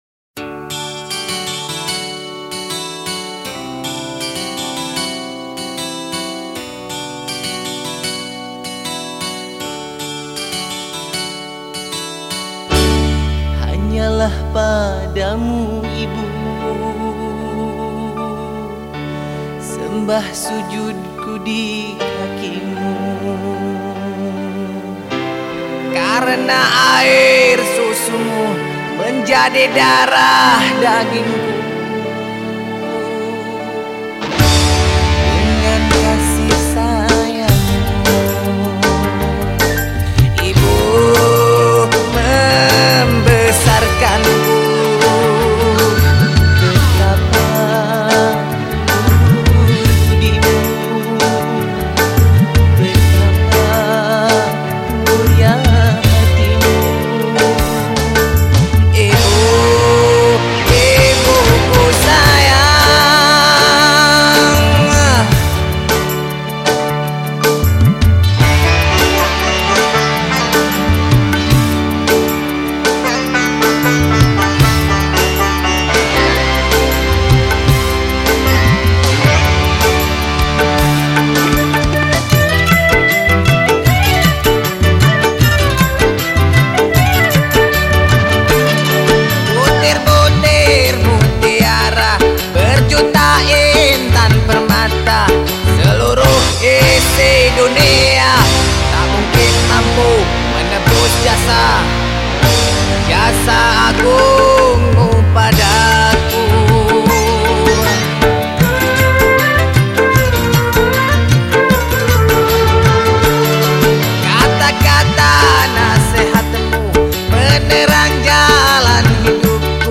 Genre Musik                              : Rock, Dangdut
Instrumen                                   : Vokal